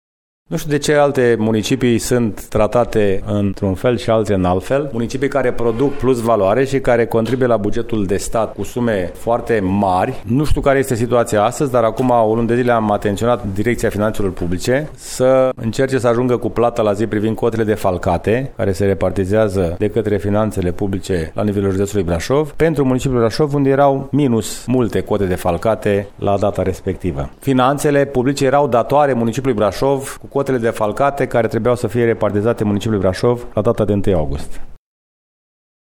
Municipiul Brașov așteaptă și acum sumele pe impozitul pe venit, cu atât mai mult cu cât la rectificarea bugetară recentă, municipiul nu a primit nimic, după cum susține primarul George Scripcaru: